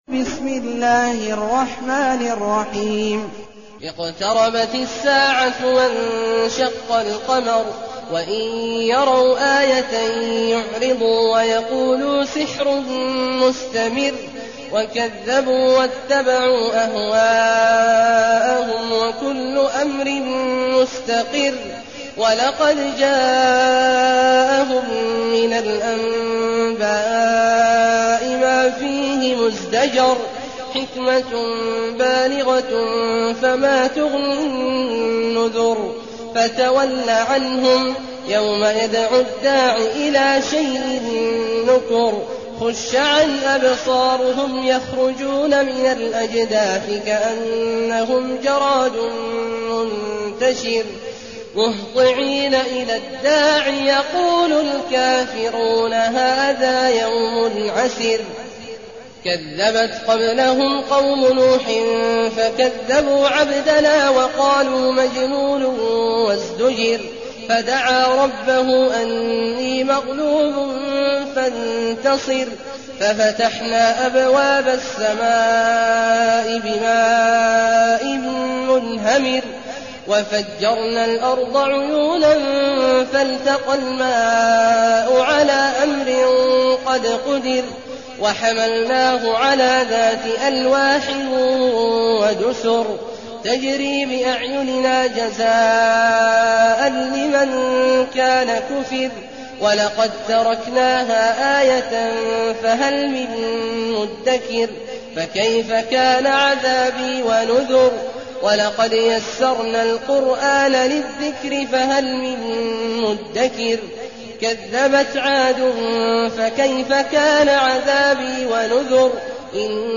المكان: المسجد الحرام الشيخ: عبد الله عواد الجهني عبد الله عواد الجهني القمر